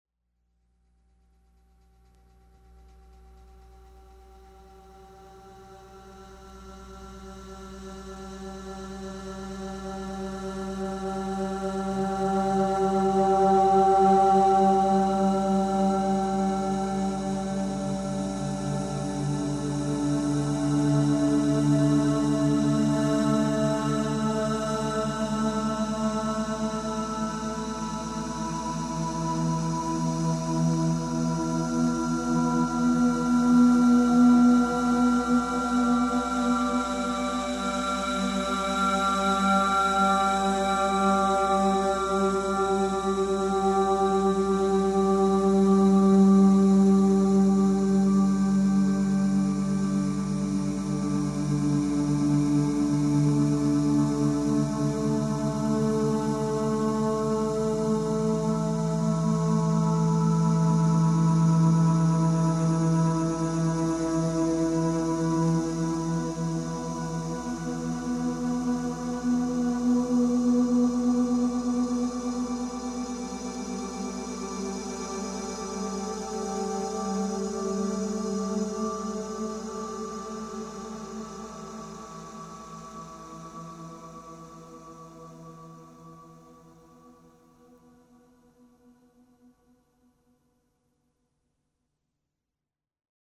guitars, basses, keyboards, programming